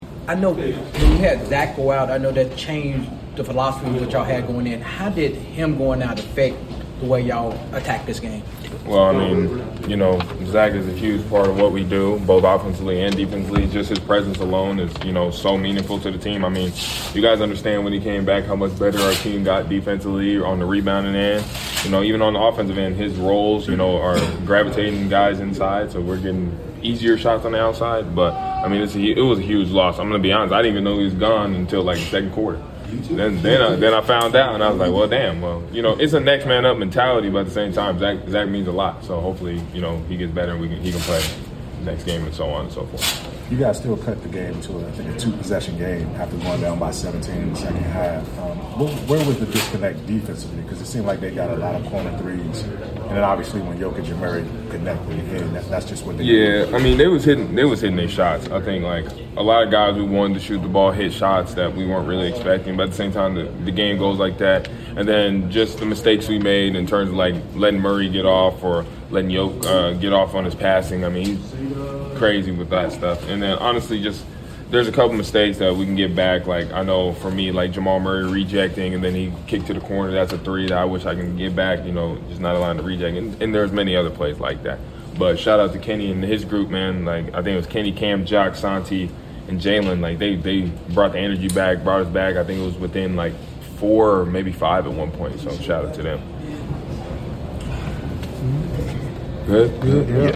Memphis Grizzlies Forward Cedric Coward Postgame Interview after losing to the Denver Nuggets at FedExForum.